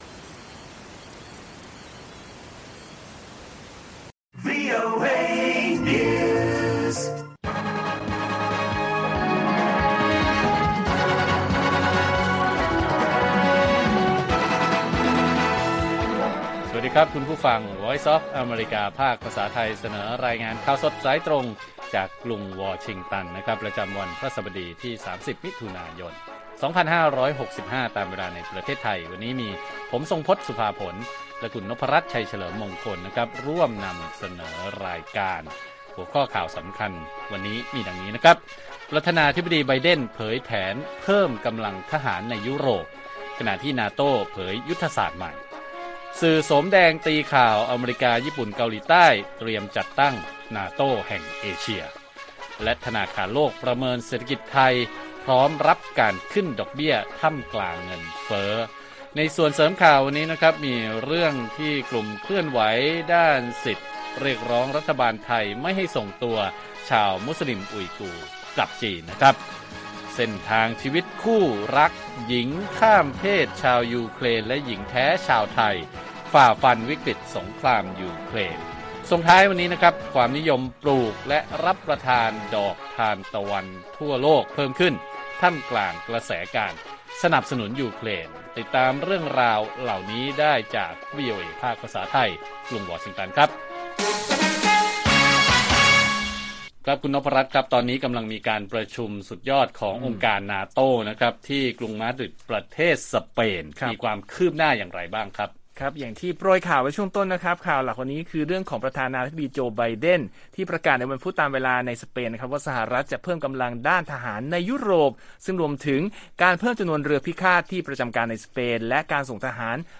ข่าวสดสายตรงจากวีโอเอ ไทย พฤหัสฯ 30 มิ.ย. 65